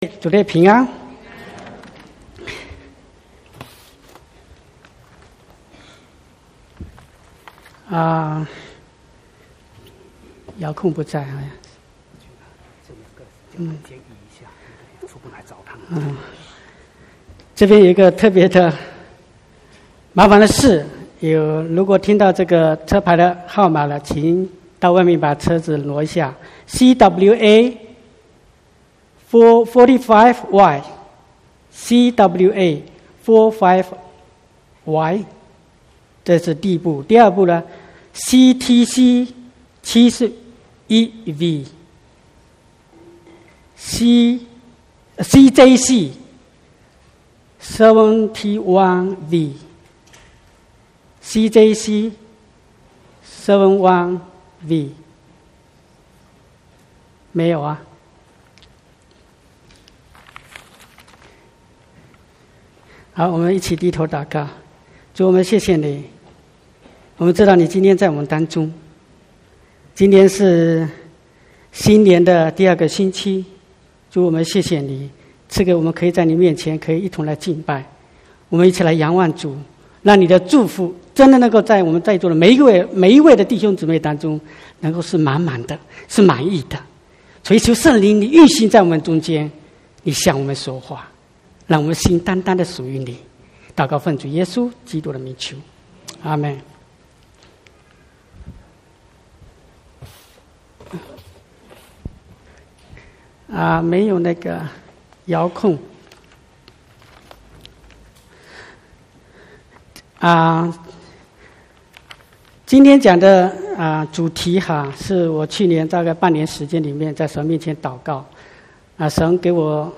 12/1/2020 國語堂講道